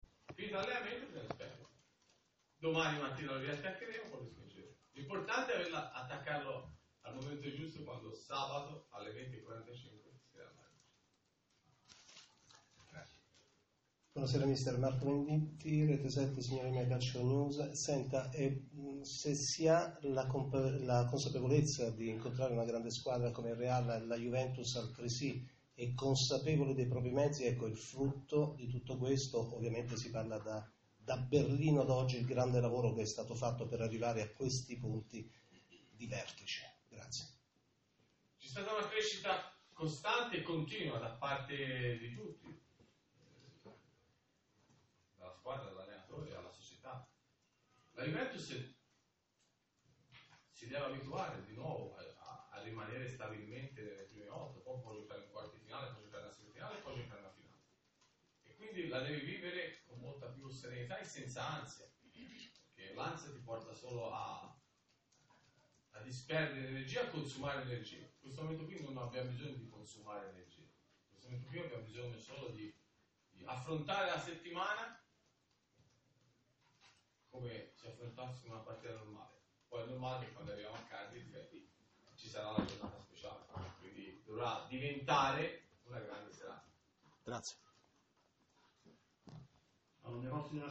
Conferenza stampa allo Juventus Stadium e domanda al mister Allegri